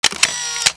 camera1.wav